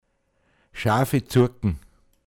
pinzgauer mundart
Schafezuaggn, m. Schaflspitze (Leoganger Steinberge)